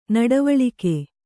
♪ naḍavaḷivaḷike